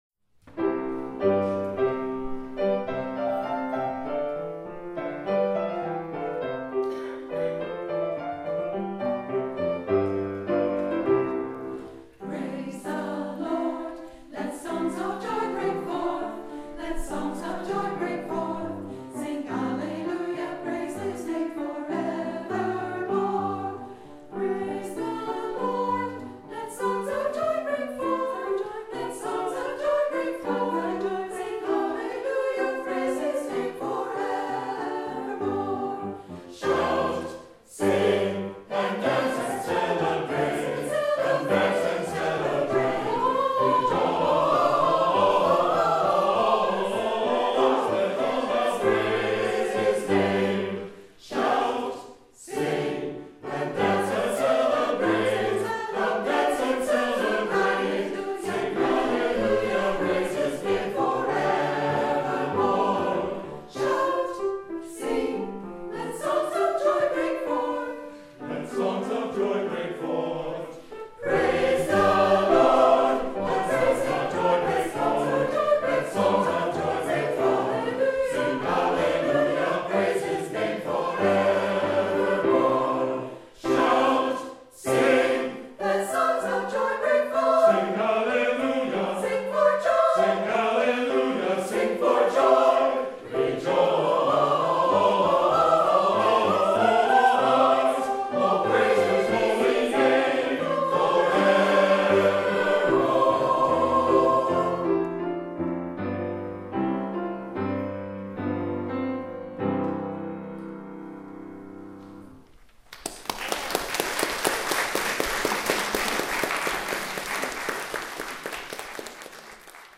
The twenty plus member choir is SATB (soprano, alto, tenor, bass).
Listen to our Choir: